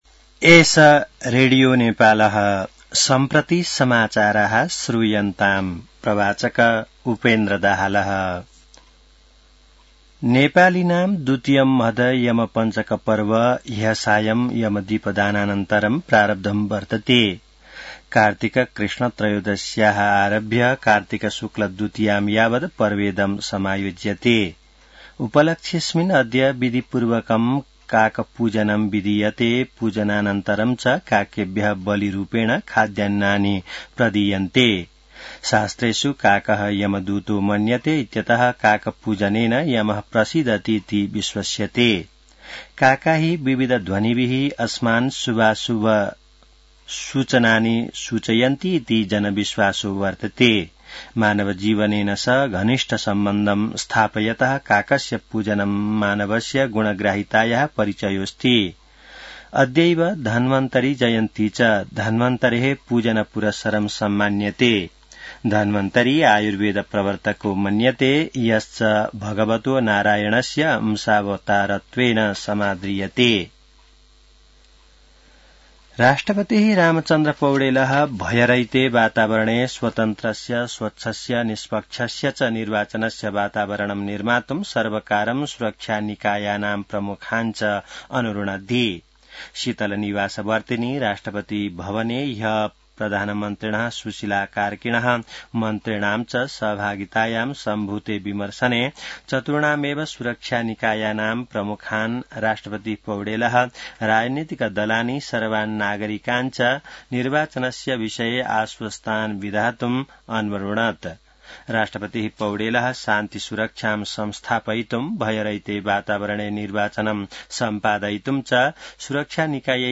संस्कृत समाचार : २ कार्तिक , २०८२